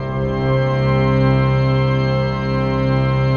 PAD 46-2.wav